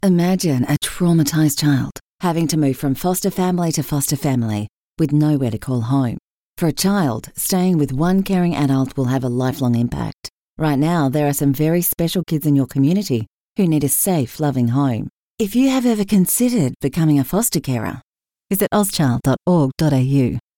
Full-Time Spanish Voice Actor.
Yng Adult (18-29) | Adult (30-50) Full-Time Spanish Voice Actor.
A voice style that is flexible, articulate and suited to any project. This voice actor can sound young and modern, mature and classy, or old and wise -- perfect for commercials or narrations.